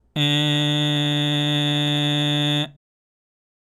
喉頭は普段の状態でグーで鼻口